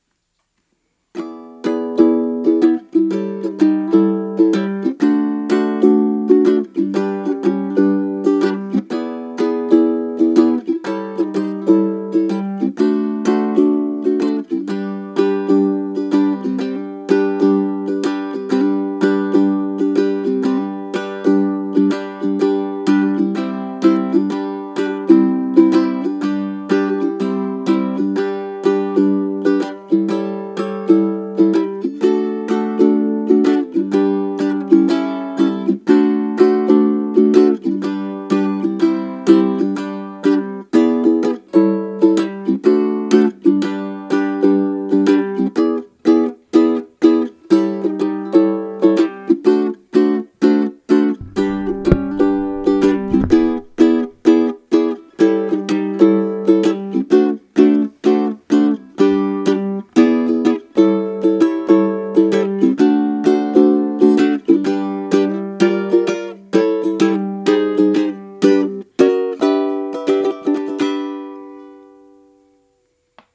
Super Tenor Ukulele model
The Tenor Ukulele (Style 2) is designed for players who crave a richer, more refined tonal palette.
Sound Character
Experience the depth and brilliance of the Super Tenor Ukulele
Super-Tenor-Sound-Sample.mp3